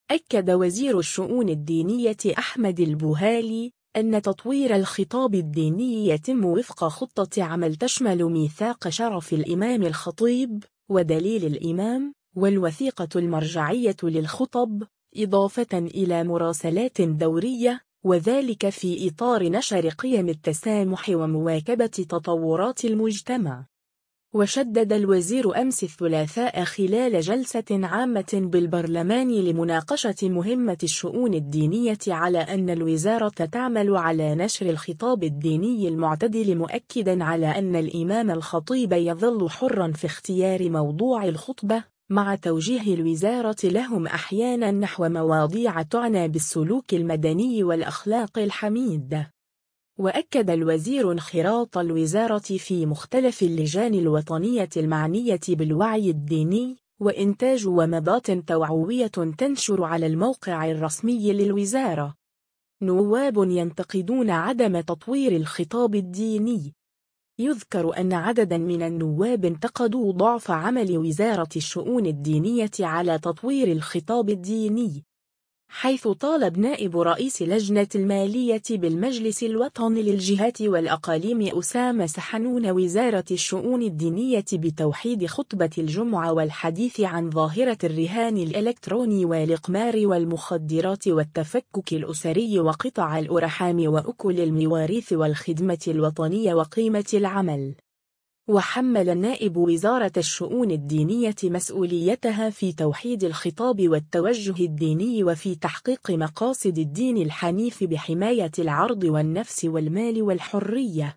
وشدّد الوزير أمس الثلاثاء خلال جلسة عامة بالبرلمان لمناقشة مهمة الشؤون الدينية على أن الوزارة تعمل على نشر الخطاب الديني المعتدل مؤكدا على أنّ الإمام الخطيب يظلّ حرّاً في اختيار موضوع الخطبة، مع توجيه الوزارة لهم أحياناً نحو مواضيع تُعنى بالسلوك المدني والأخلاق الحميدة.